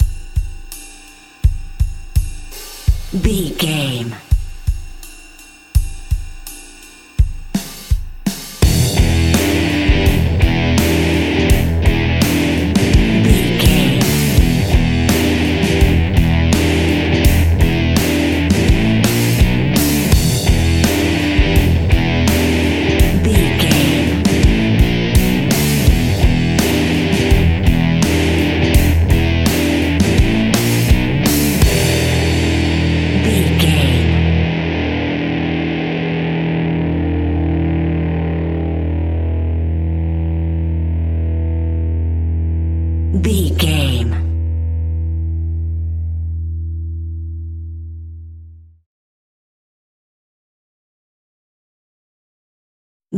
Epic / Action
Fast paced
Aeolian/Minor
hard rock
heavy metal
blues rock
distortion
Rock Bass
heavy drums
distorted guitars
hammond organ